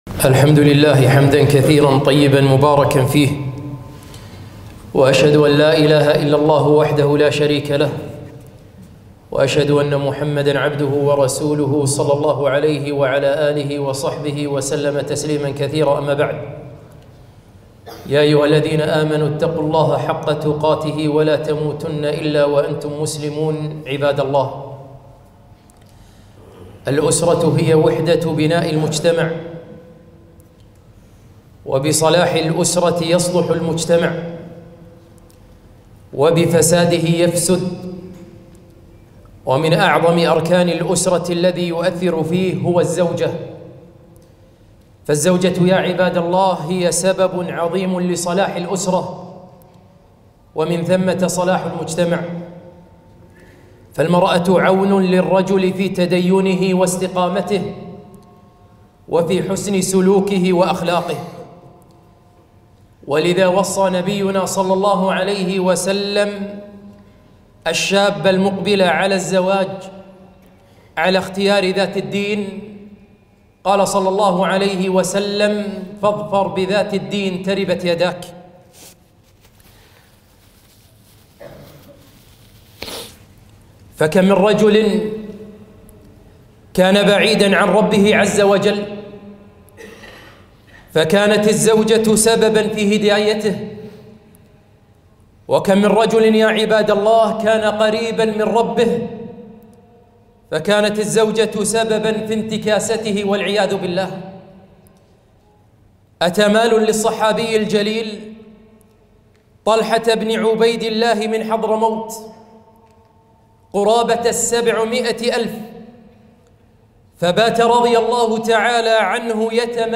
خطبة - زوجات صالحات